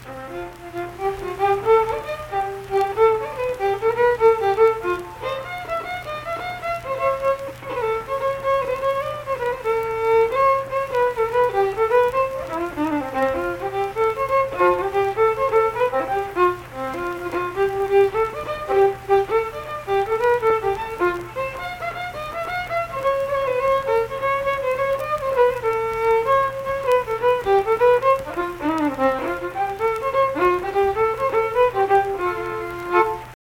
Unaccompanied vocal and fiddle music
Verse-refrain 2(1).
Instrumental Music
Fiddle